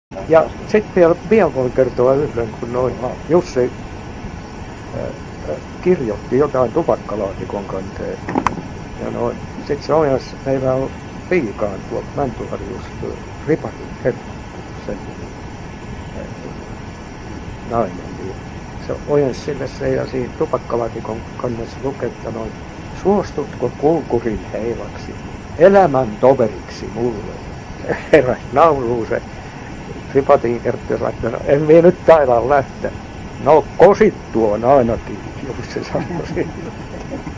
tarinointia